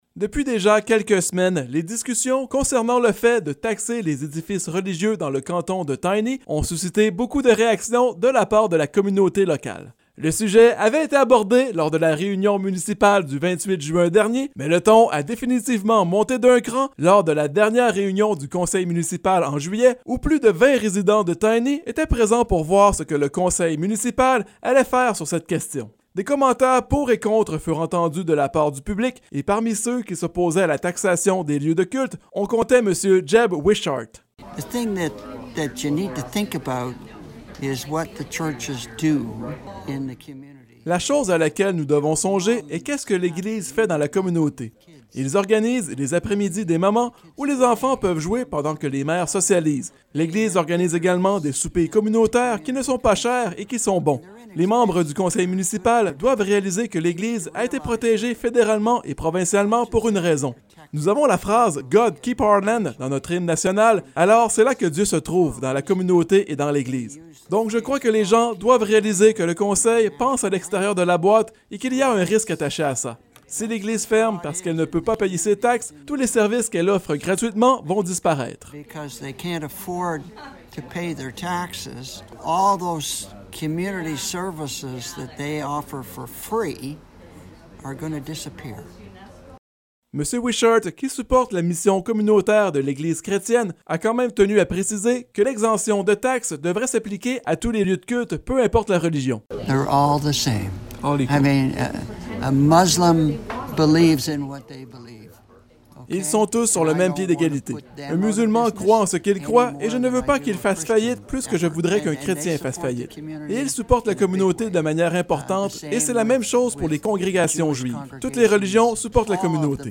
Reportage-sur-la-taxe-des-eglise-de-Tiny.mp3